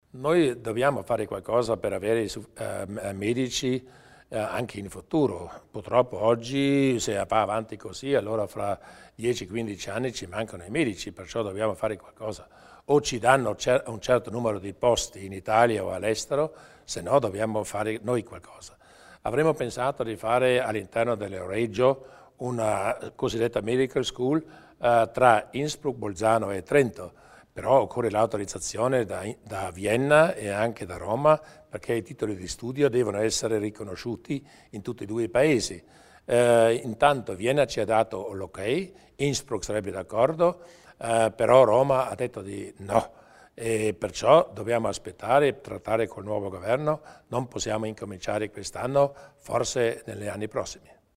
Il Presidente Durnwalder illustra le prospettive della Medical School